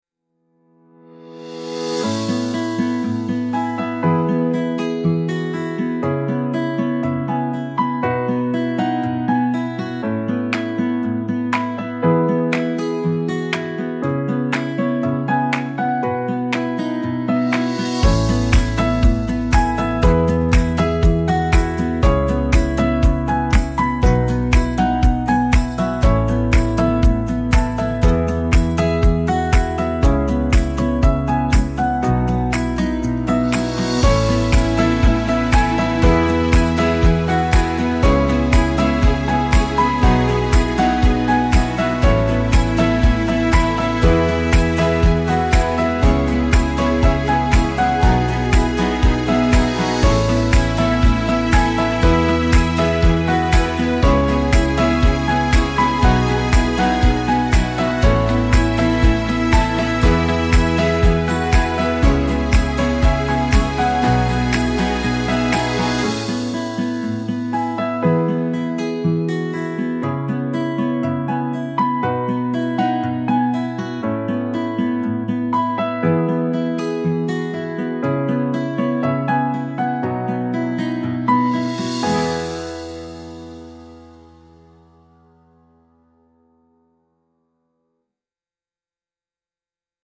bright acoustic pop instrumental with claps, piano and uplifting strings